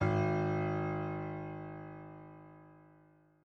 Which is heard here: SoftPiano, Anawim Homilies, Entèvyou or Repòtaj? SoftPiano